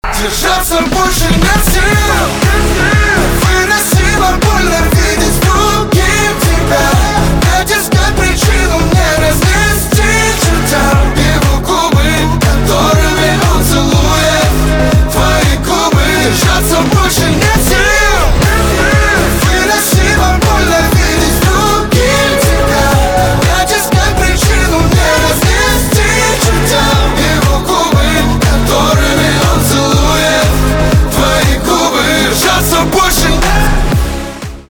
поп
битовые , басы , грустные , печальные
чувственные